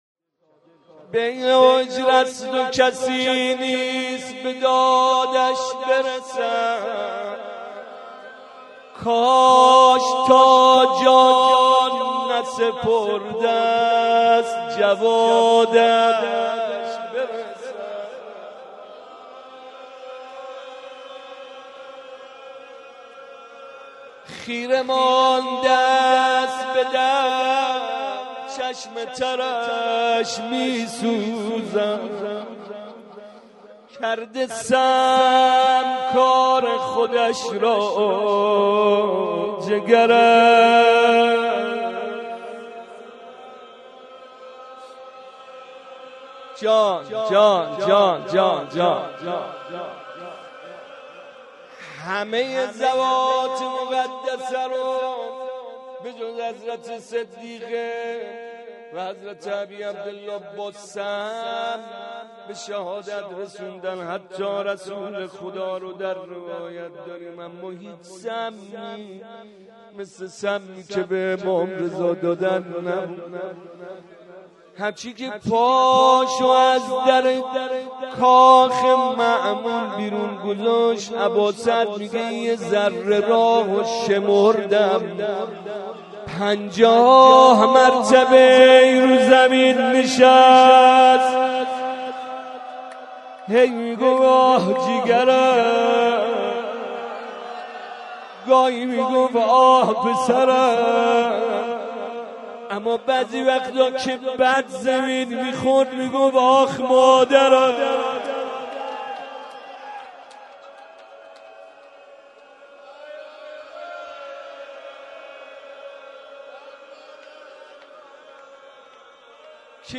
02.rozeh.mp3